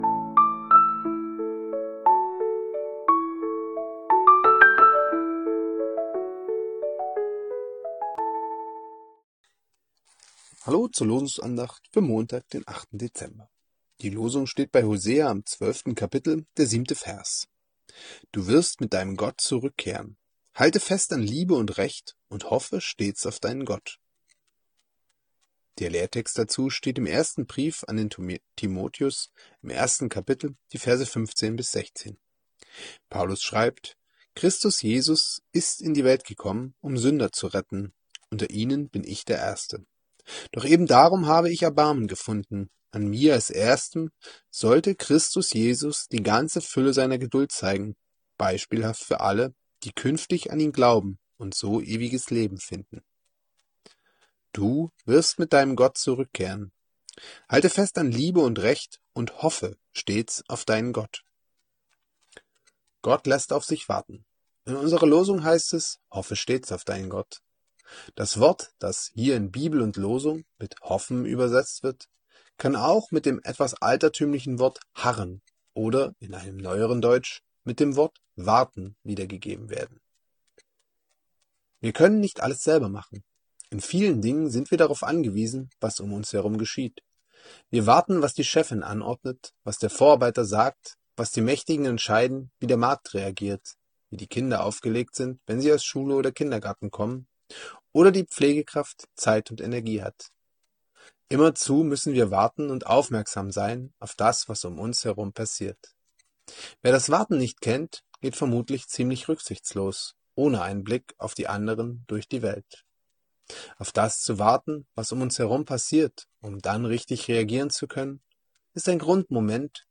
Losungsandacht für Montag, 08.12.2025